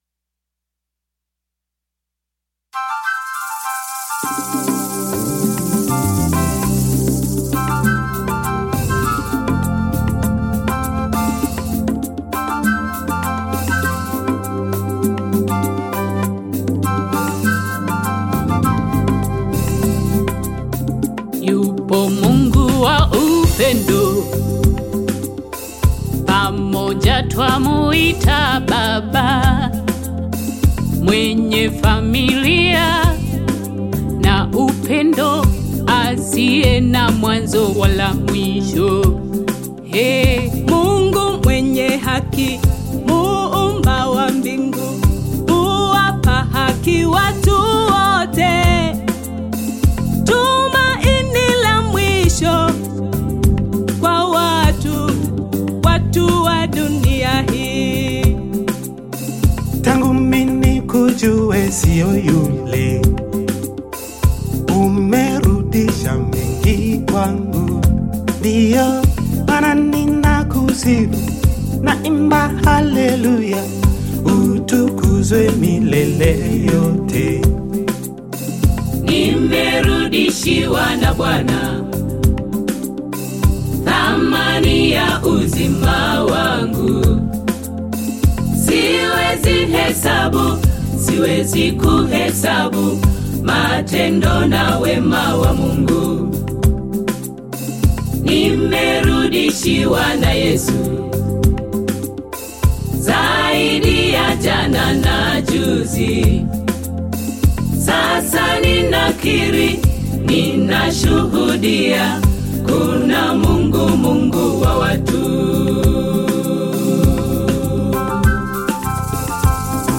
Tanzanian Gospel choir